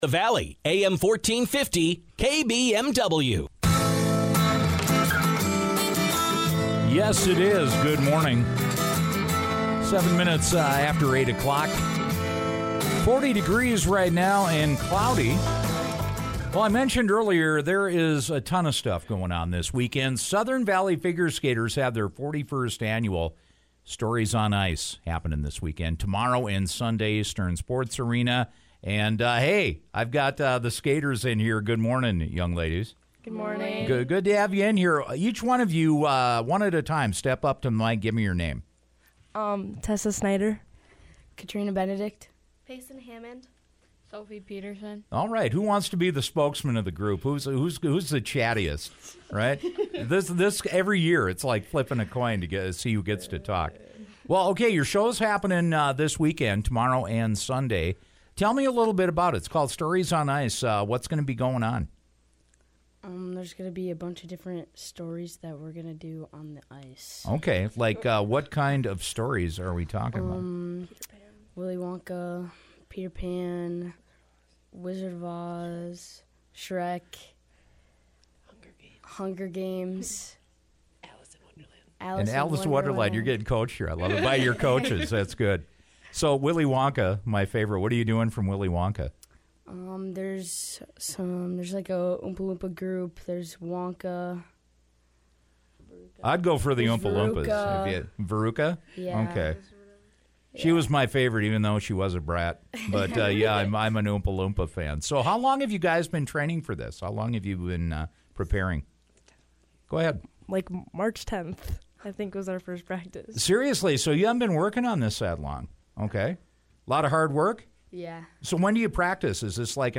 Southern Valley Figure Skaters take to the ice at Stern Arena tomorrow and Sunday! Skaters and coaches dropped by The Morning Show today to talk about their biggest event of the year!